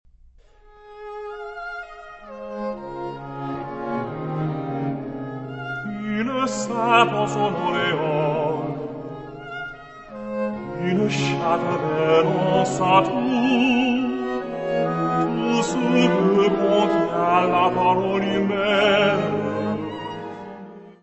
Área:  Música Clássica